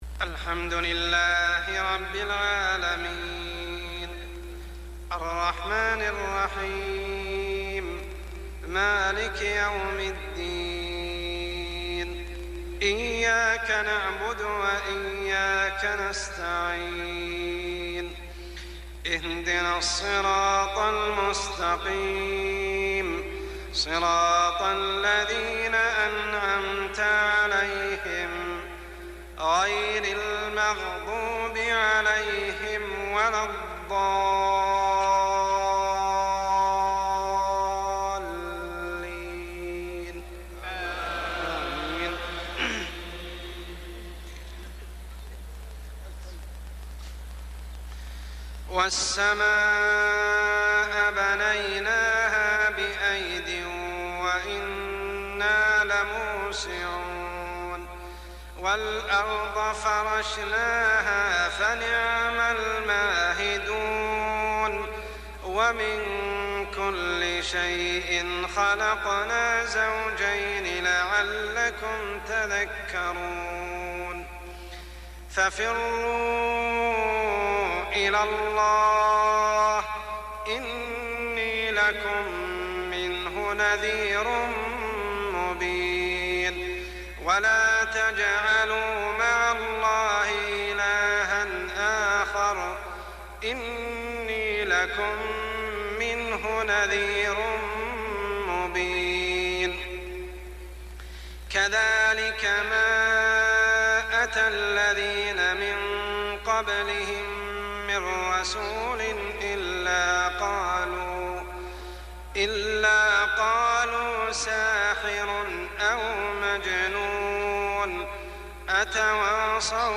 صلاة العشاء ( العام غير مذكور ) من سورة الذاريات 47-60 | Isha prayer surah Adh-Dhaariyat > 1420 🕋 > الفروض - تلاوات الحرمين